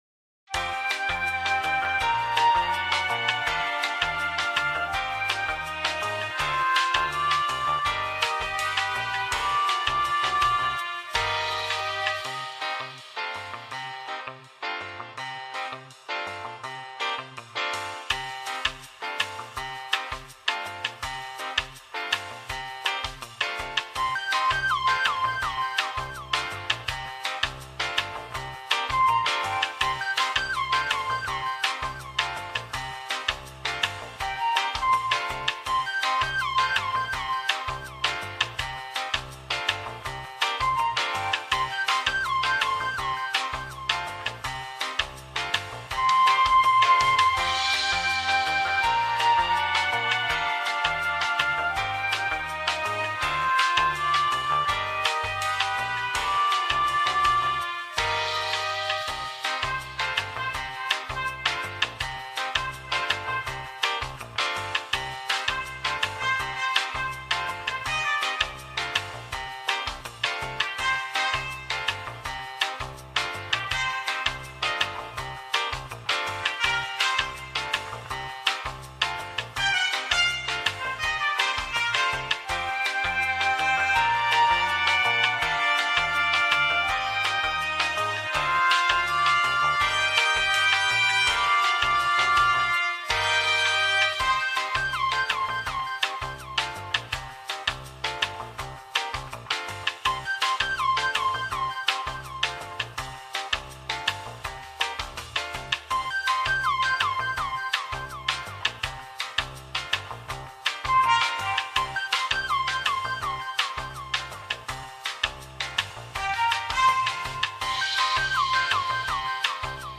backgroundMusic-8fd26874.mp3